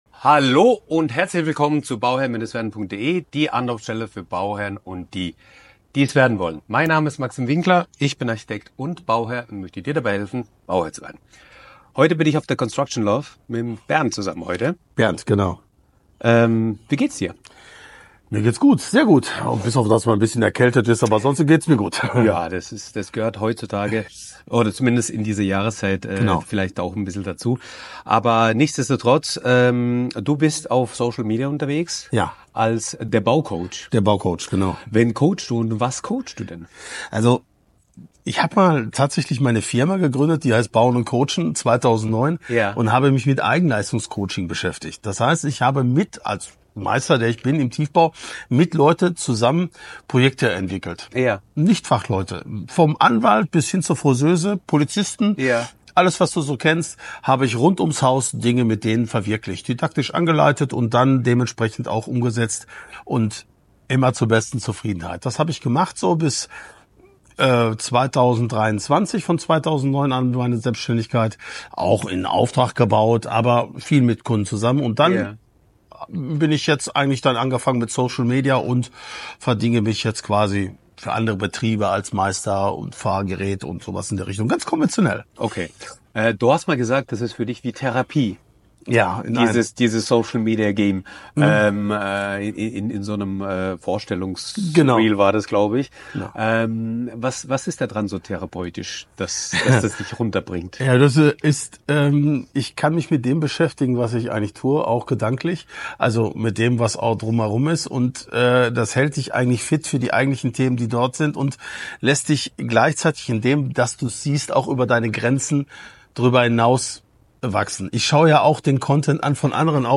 Ein inspirierendes Gespräch über Baucoaching und Social Media